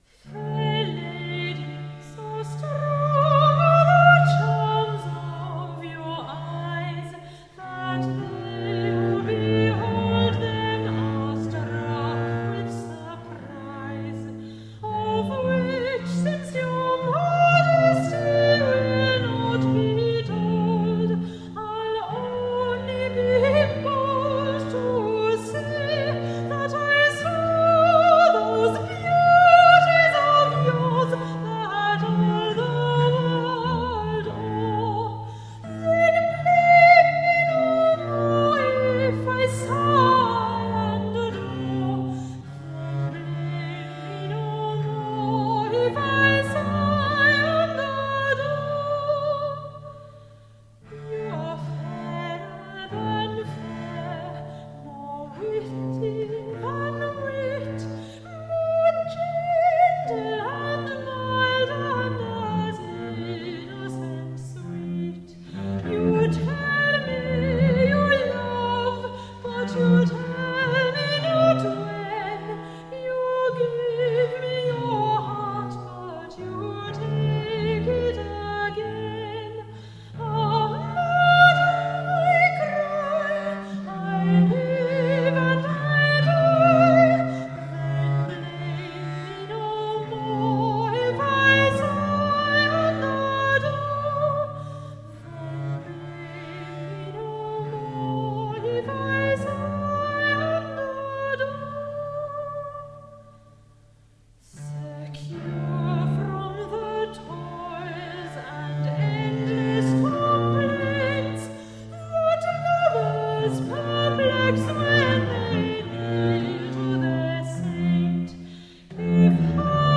accompanied by theorbo and bass viol.
Classical Singing
Theorbo, Viola da Gamba